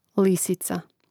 lìsica lisica